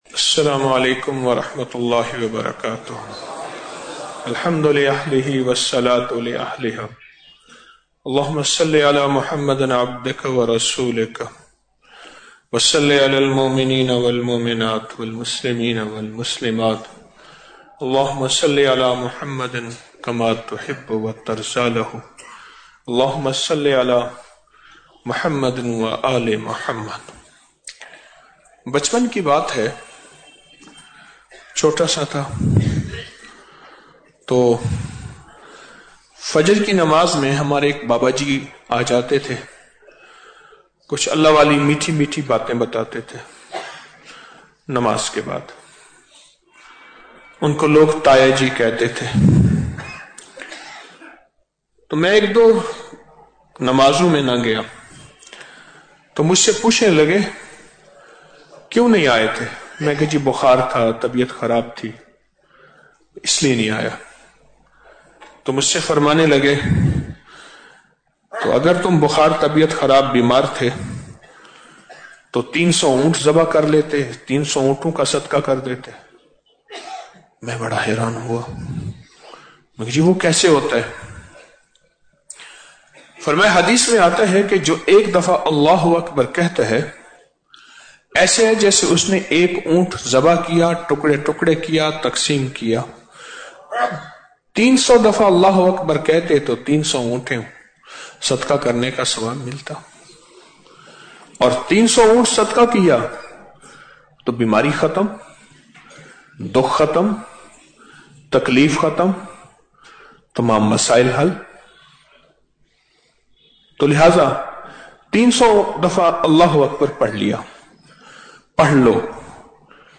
Audio Speech - 05 Ramadan After Salat Ul Taraweeh - 5 March 2025